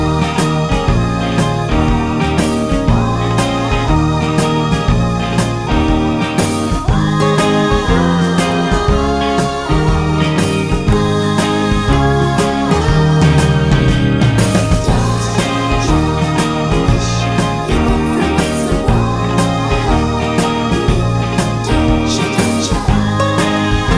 for duet Rock 'n' Roll 3:53 Buy £1.50